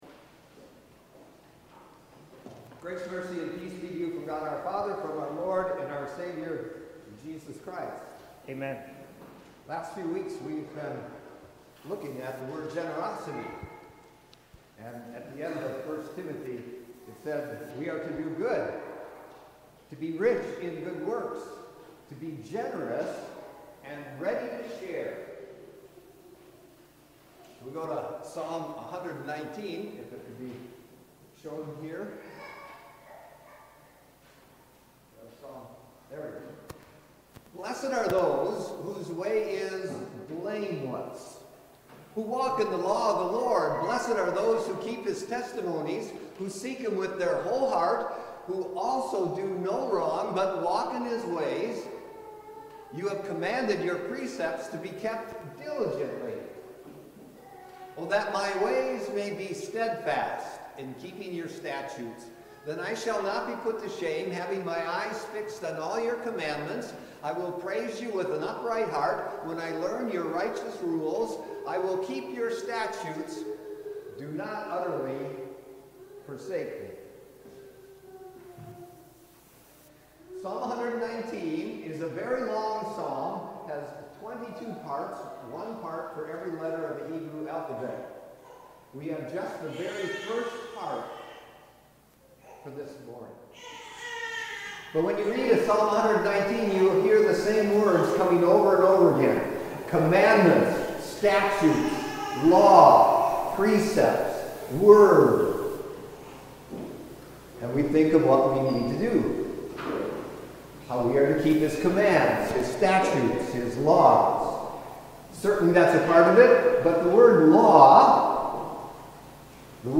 Sermons | Timothy Lutheran Church